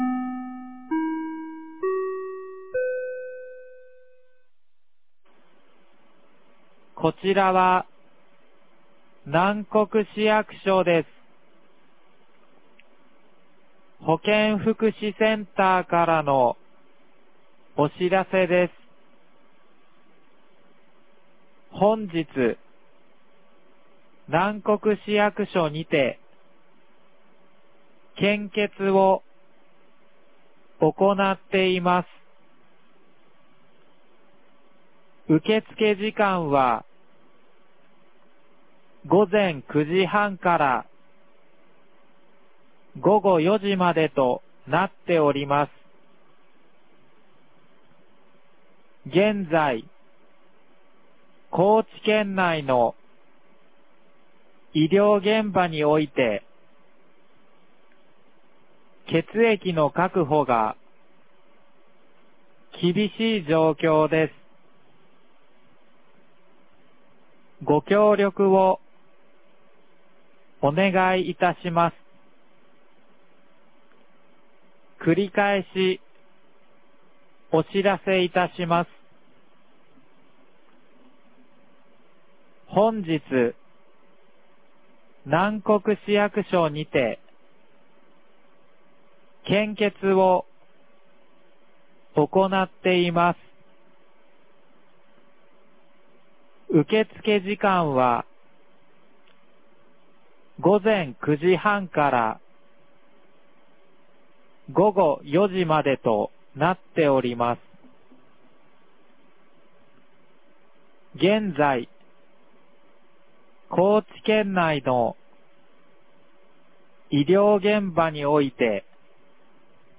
南国市放送内容
2025年01月28日 09時02分に、南国市より放送がありました。